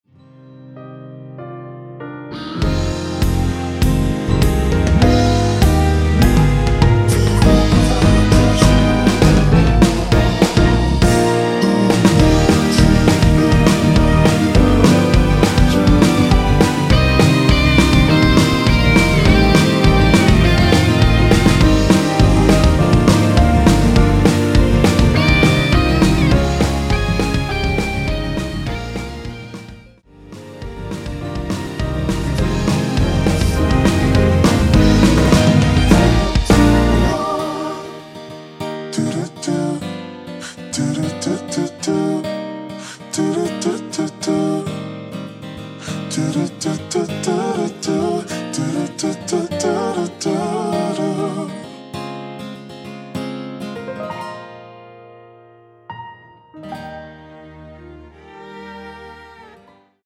원키에서(-3)내린 코러스 포함된 MR입니다.
Eb
앞부분30초, 뒷부분30초씩 편집해서 올려 드리고 있습니다.
중간에 음이 끈어지고 다시 나오는 이유는